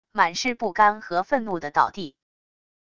满是不甘和愤怒的倒地wav音频